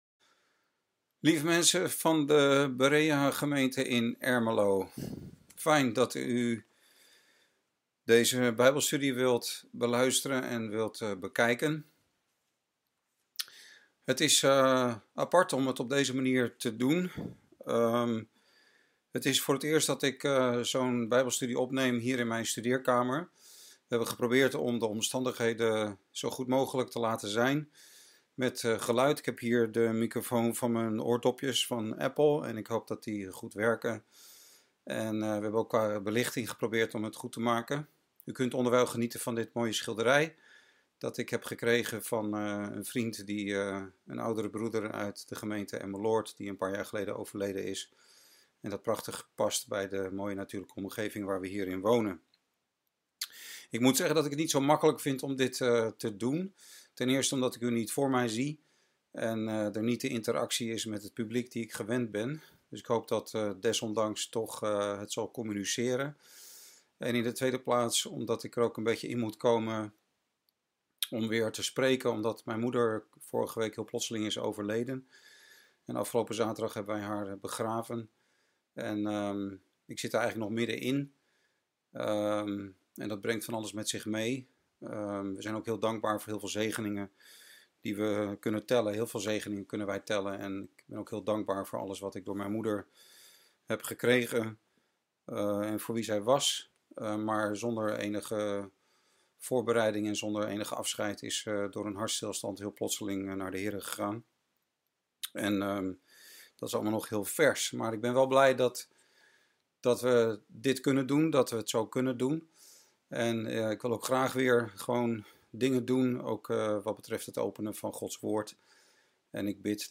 Studie-Zie-de-Mens-Zie-de-Koning.mp3